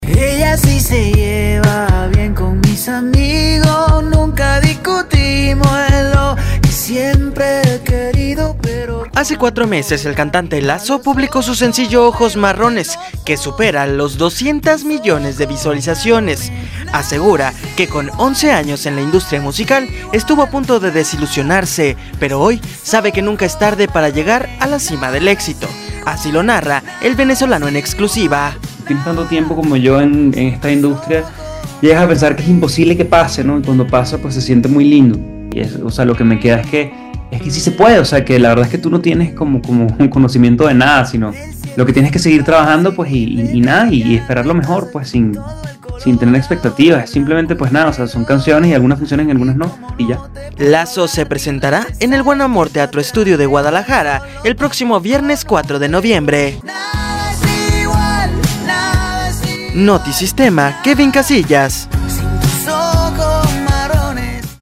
Asegura que con once años en la industria musical, estuvo a punto de desilusionarse, pero hoy sabe que nunca es tarde para llegar a la cima del éxito. Así lo narra el venezolano en exclusiva.